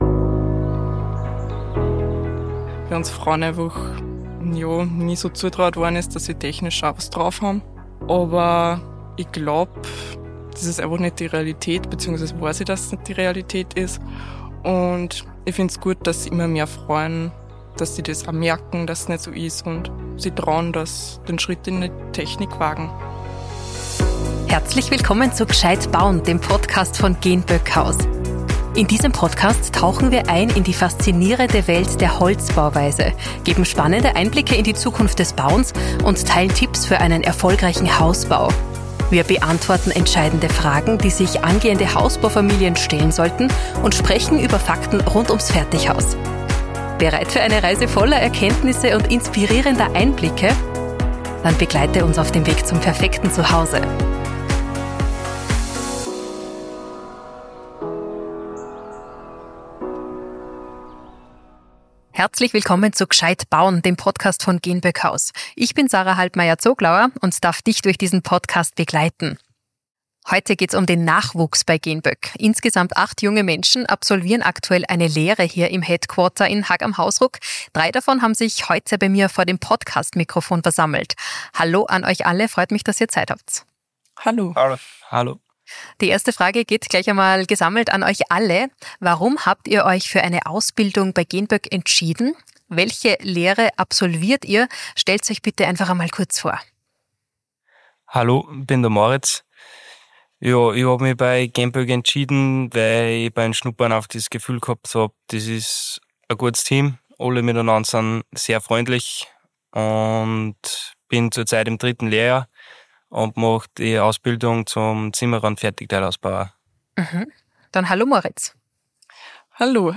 In Episode 19 von „gscheit bauen“ kommen die jüngsten Arbeitskräfte von Genböck zu Wort.